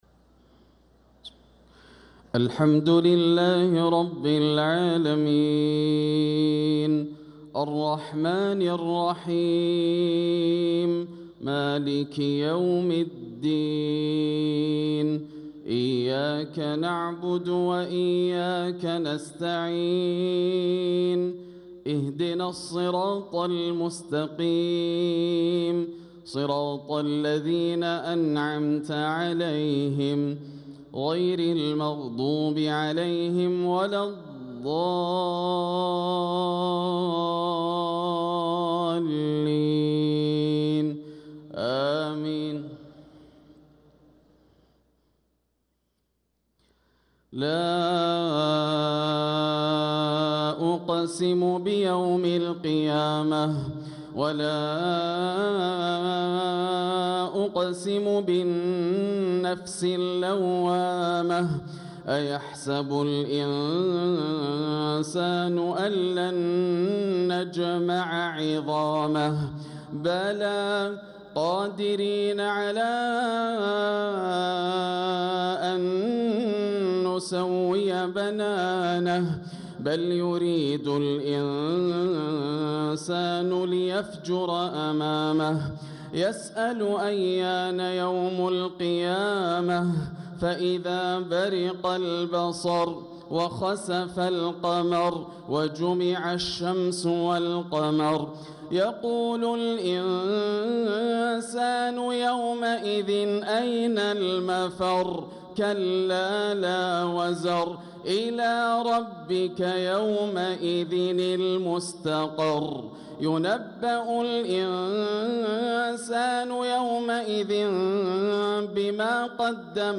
صلاة العشاء للقارئ ياسر الدوسري 17 رجب 1446 هـ
تِلَاوَات الْحَرَمَيْن .